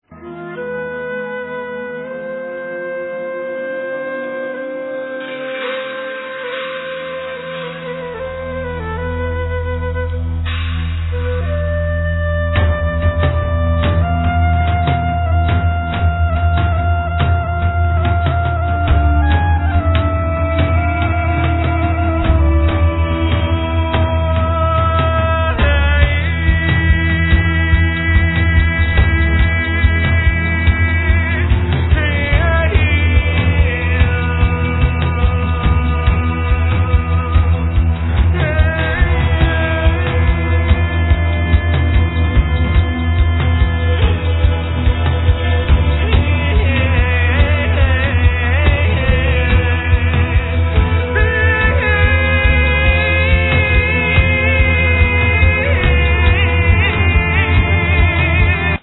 Guitar, Rhythm guitar, Guitar textures
Trapkit Drum set
Guitar, Cello, Loops, Samples
Bass, Piano
Doumbek, Riqq, Sitar, Tar
Vocals